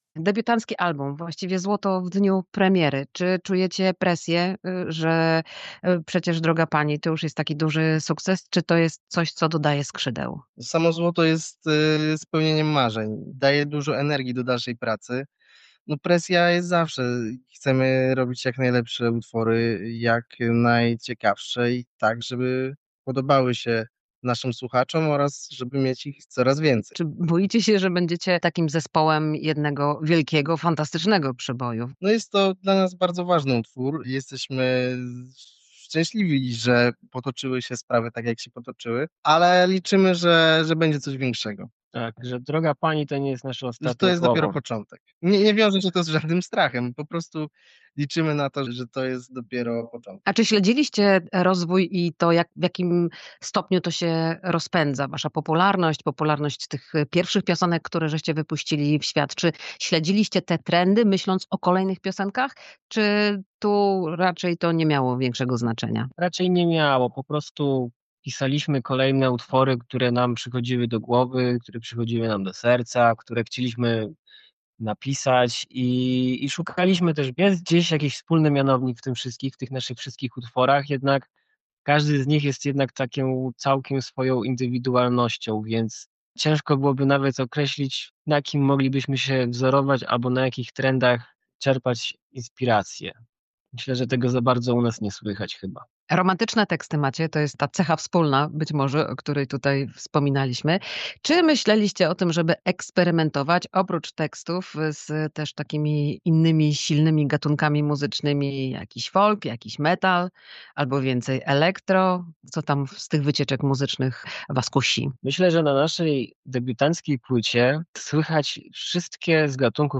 Zespół Cosmos o hitach, nowym albumie i sławie – wywiad